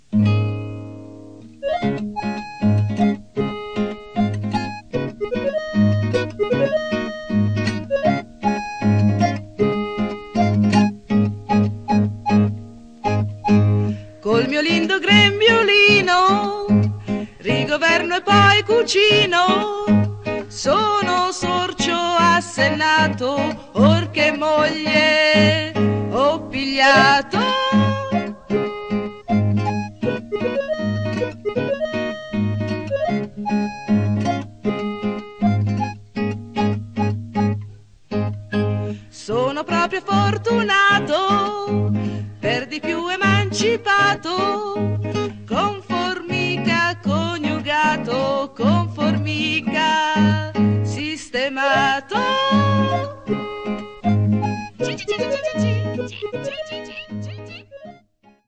Musiche di Giovanni Iaffaldano
SORCIO             (Con cappello da cuoco e mestolo, canta: Lindo grembiulino)